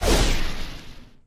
sam_dryfire_01.ogg